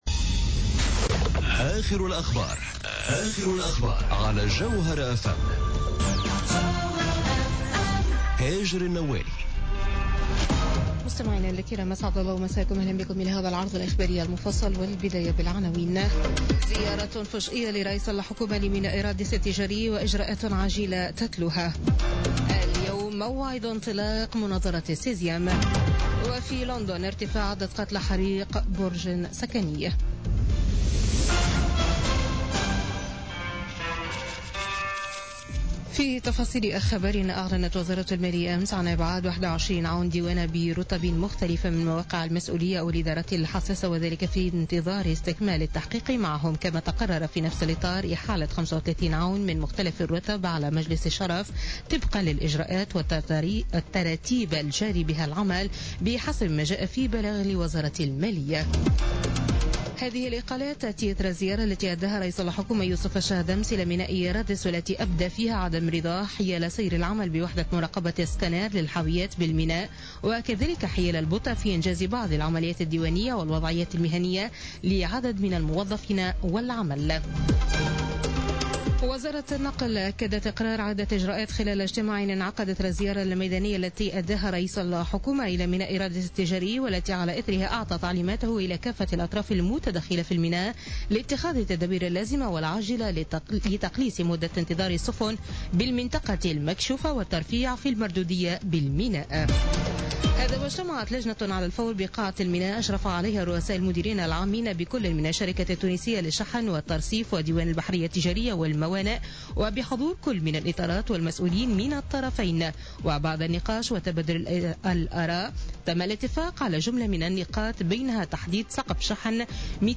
نشرة أخبار منتصف الليل ليوم الخميس 15 جوان 2017